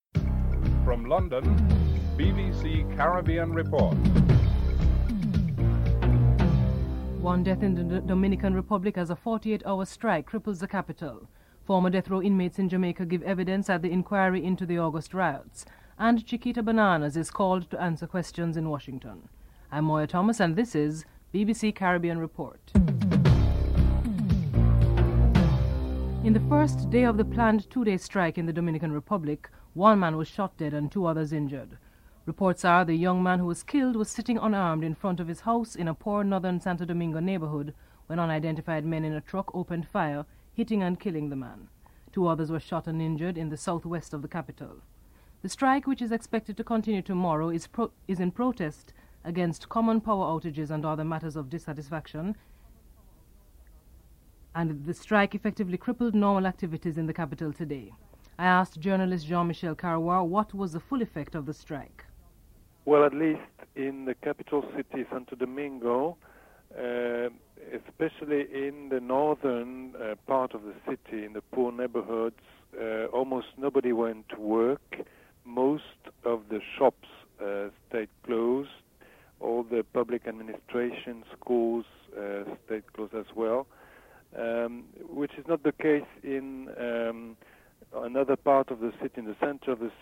1. Headlines (00:00-00:25)
A former death row prisoner is interviewed